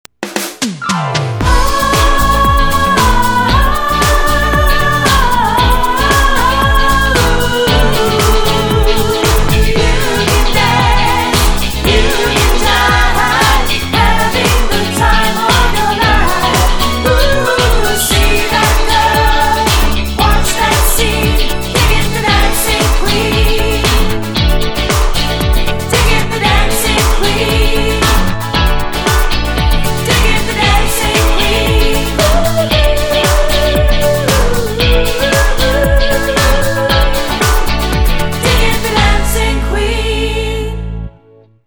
My drum parts
Dance/R&B/Disco